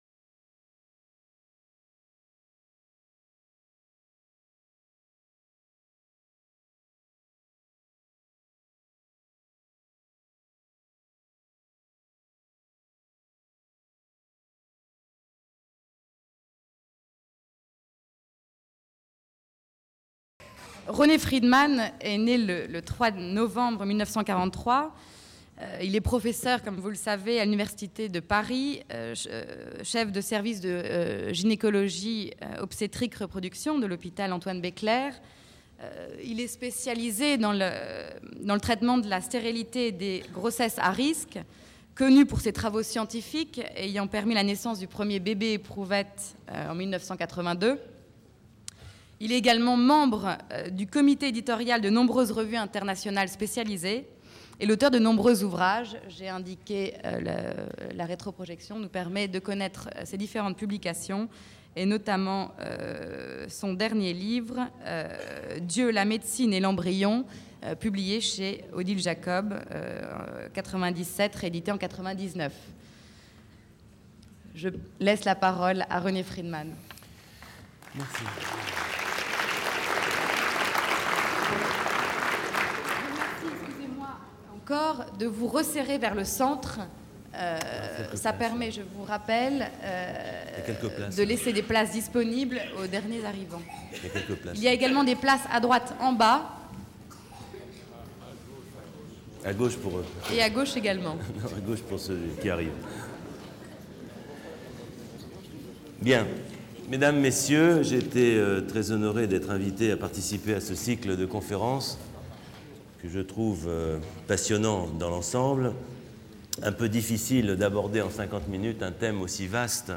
Conférence du 23 janvier 2000 par René Frydman. La maîtrise de la reproduction est centrée sur l'embryon humain.